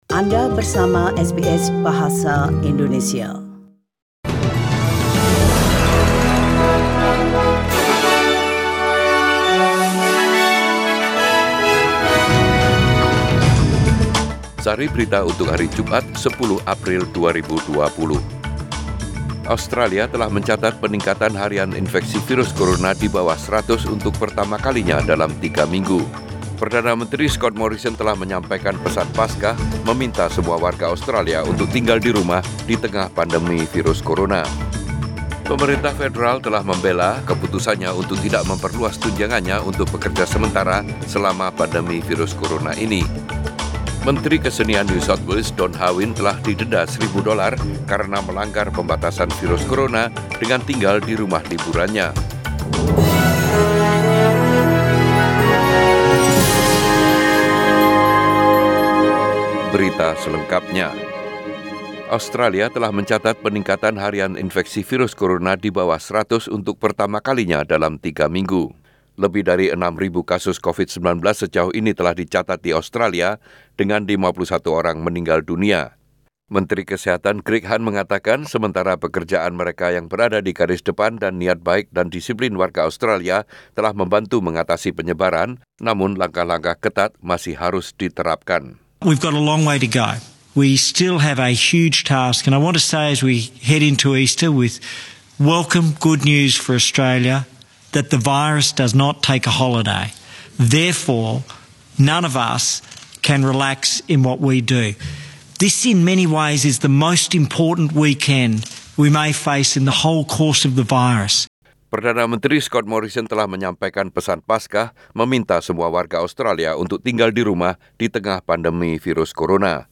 SBS Radio News in Bahasa Indonesia - 10 April 2020